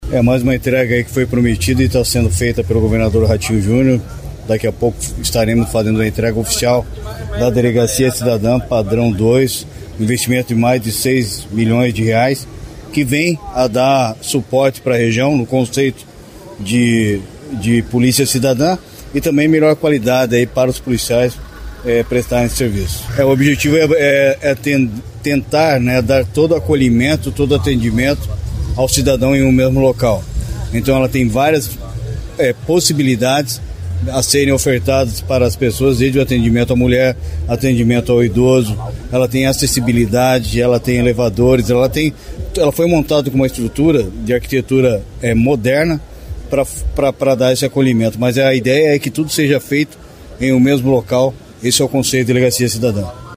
Sonora do secretário Estadual da Segurança Pública, Hudson Teixeira, sobre a Delegacia Cidadã de Cianorte